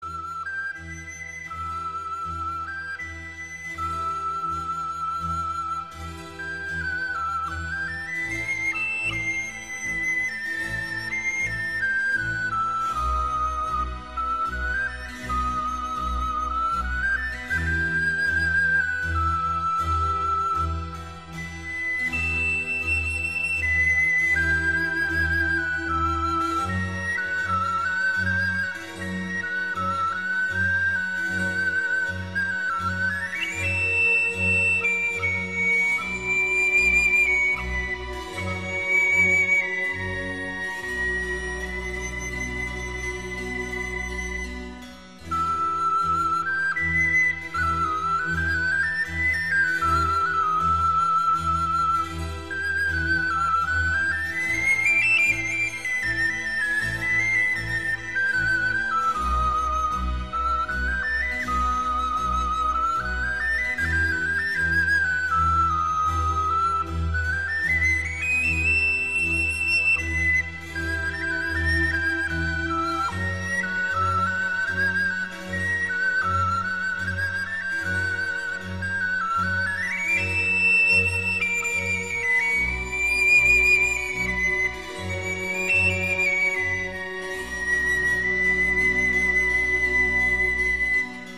* Antonio Vivaldi – Concerto for Piccolo and Strings in C Major RV 443